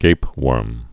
(gāpwûrm, găp-)